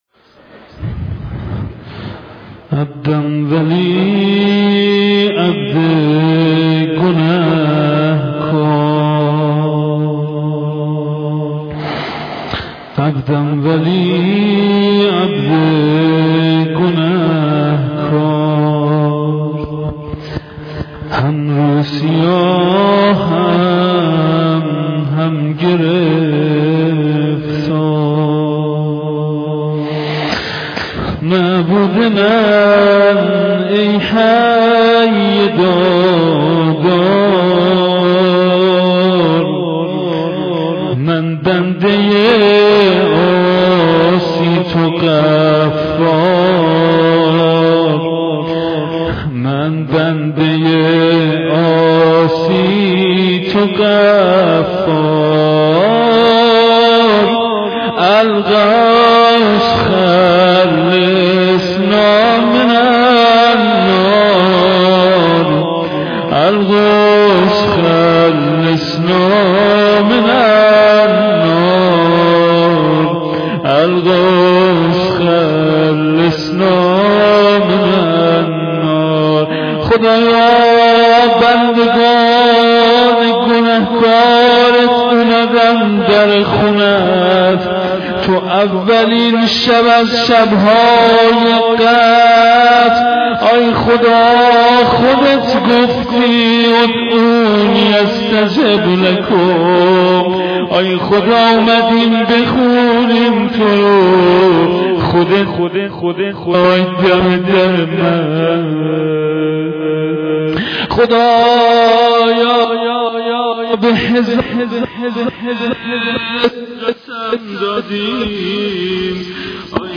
مراسم احیاقرآن به سر و روضه خوانی.mp3
مراسم-احیاقرآن-به-سر-و-روضه-خوانی.mp3